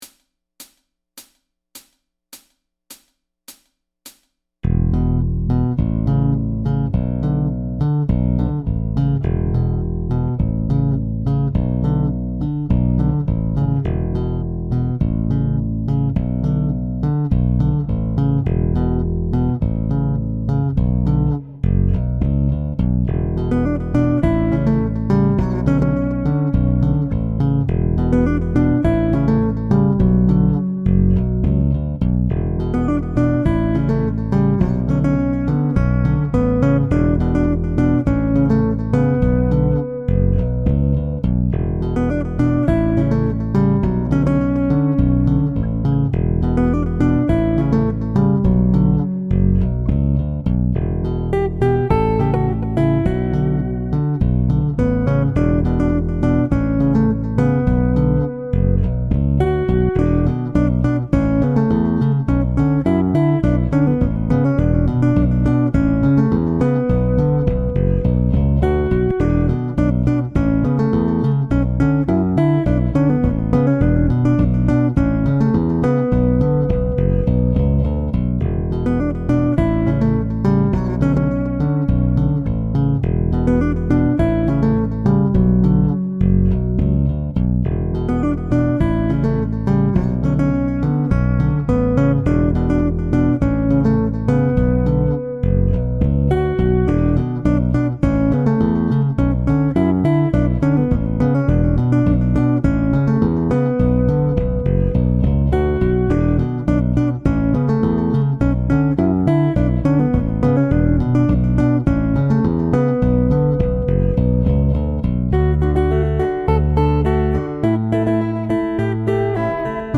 • Instrumental
The right hand plays the melody.